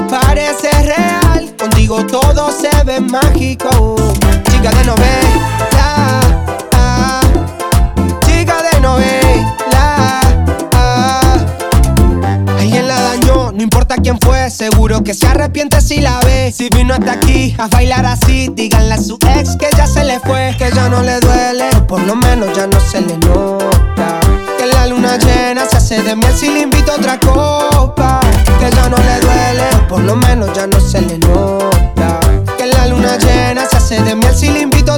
# Latin